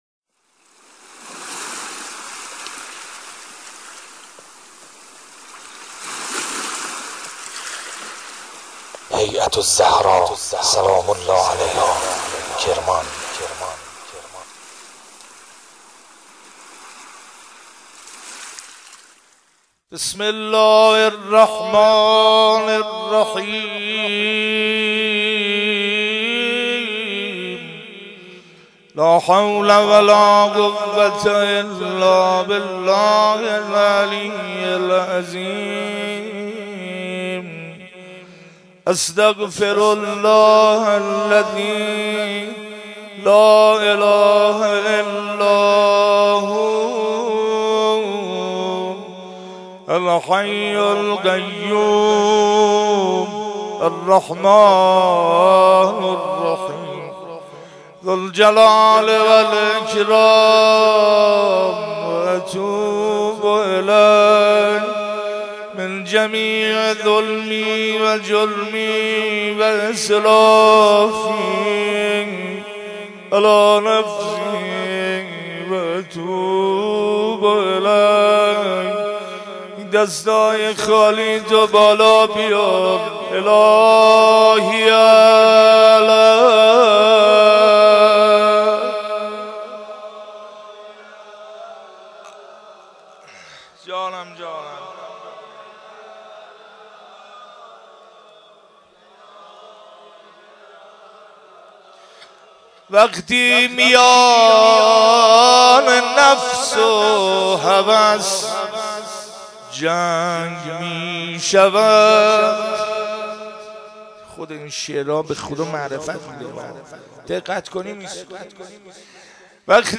جلسه هفتگی27بهمن95